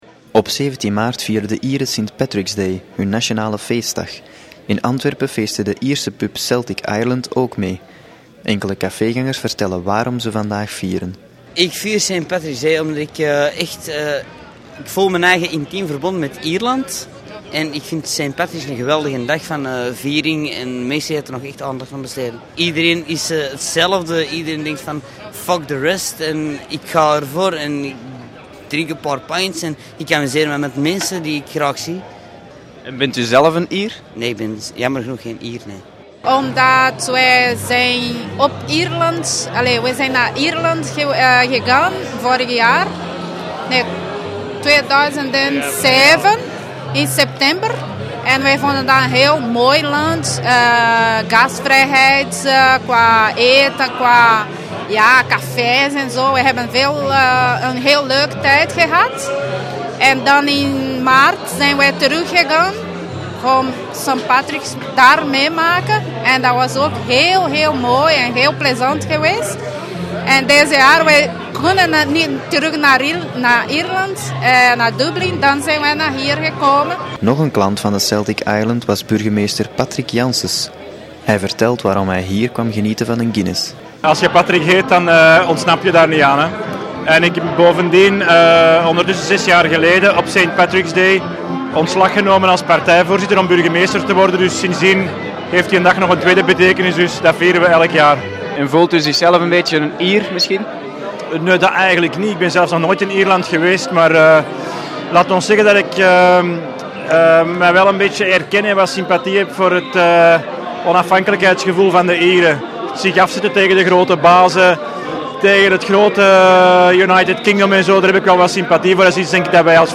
De Antwerpse burgemeester Patrick Janssens heeft in café Celtic Ireland op de Groenplaats Saint Patrick’s Day gevierd.
Een gesprek met burgemeester Patrick Janssens en enkele Saint Patrick’s-vierders.